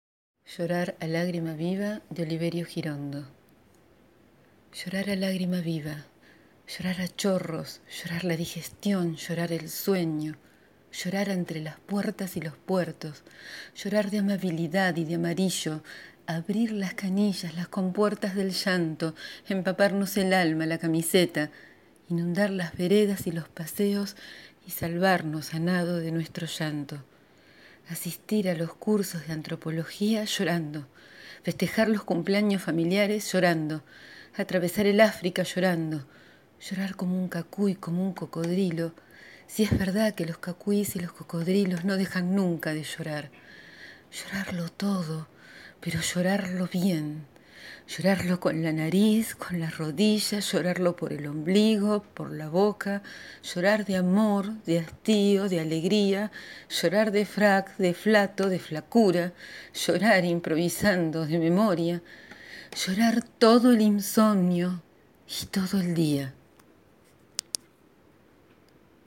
Hoy leo «Llorar a lágrima viva» de Oliverio Girondo (1891-1967).